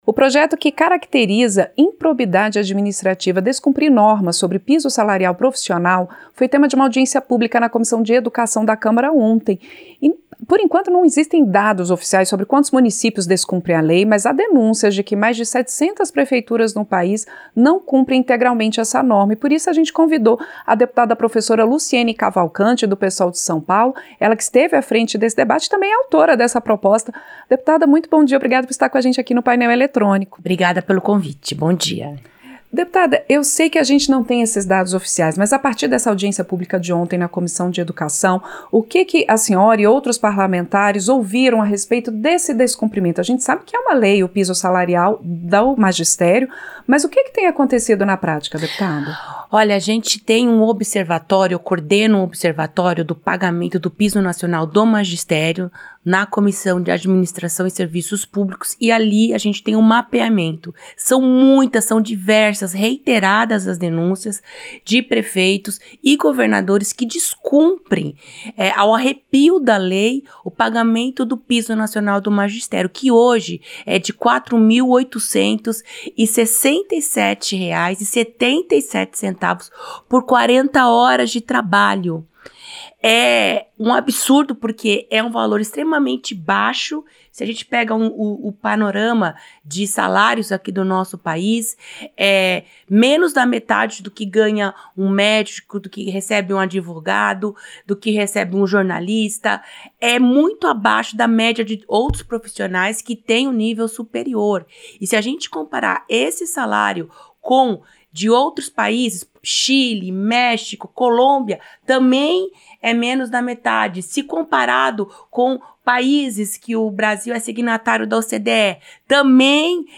Entrevista dep. Professor Luciene Cavalcante (PSOL-SP)